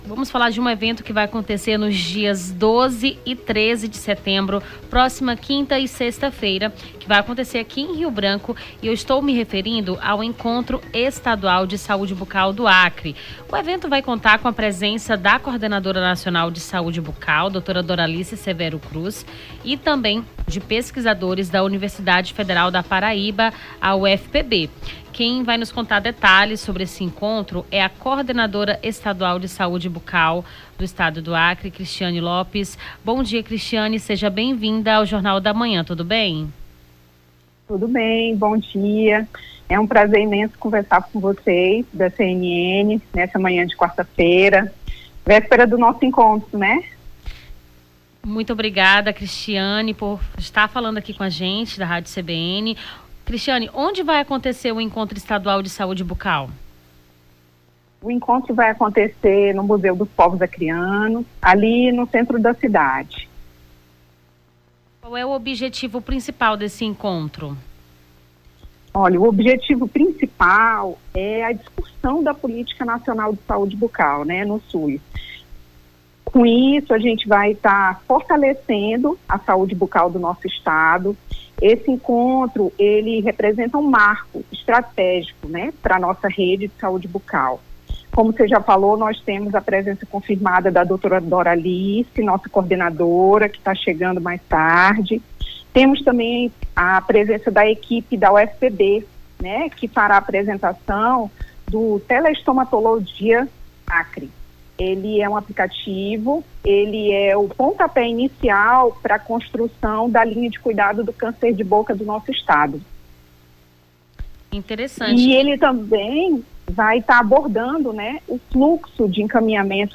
Nome do Artista - CENSURA - ENTREVISTA SAÚDE BUCAL (11-09-24).mp3